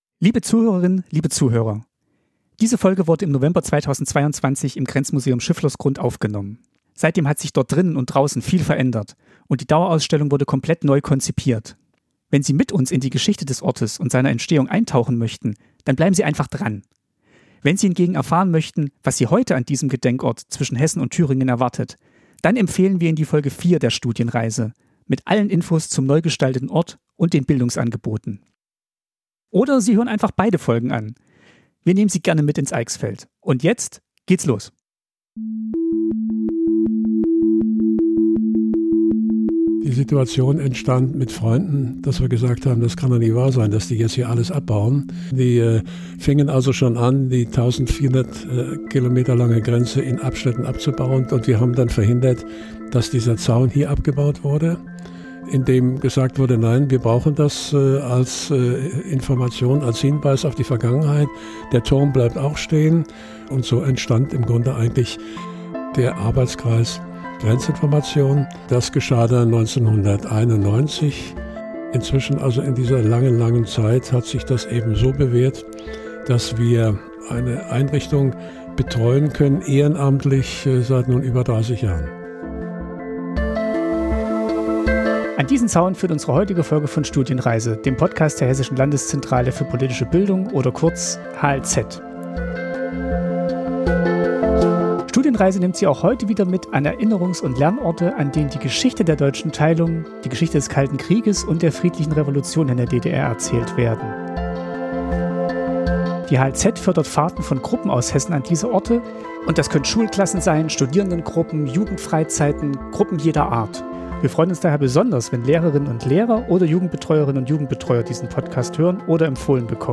In der zweiten Folge sind wir zu Gast im Grenzmuseum Schifflersgrund in der Nähe der hessischen Kurstadt Bad Sooden-Allendorf. In dieser Folge treffen wir auf zwei Menschen, die über Vergangenheit und Gegenwart des Museums berichten.